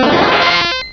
Cri de Stari dans Pokémon Rubis et Saphir.
Cri_0120_RS.ogg